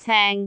speech
syllable
pronunciation
ceng6.wav